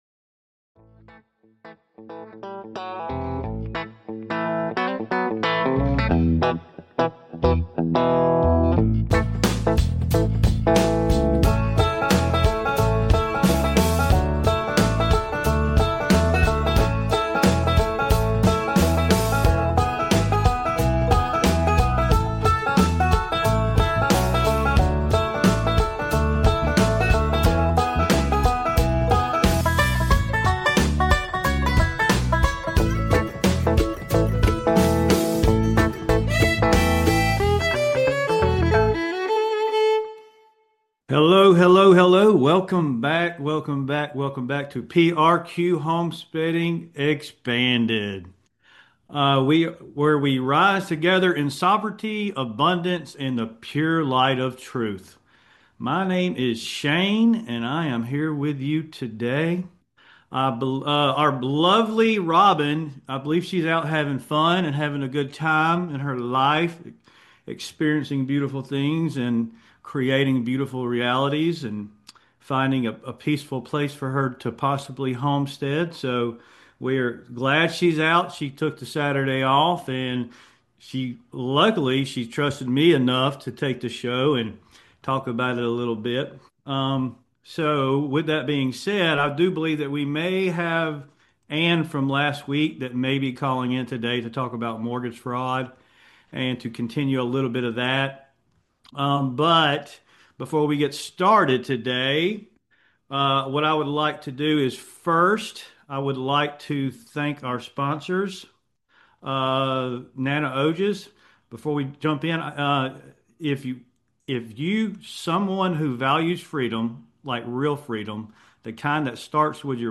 Answering caller questions